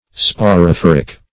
Search Result for " sporophoric" : The Collaborative International Dictionary of English v.0.48: Sporophoric \Spo`ro*phor"ic\ (-f[o^]r"[i^]k), a. (Bot.)